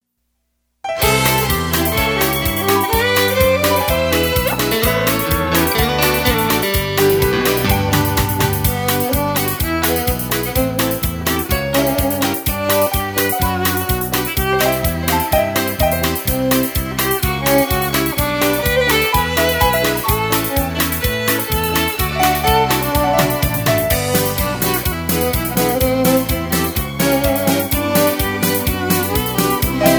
Singing Call
Inst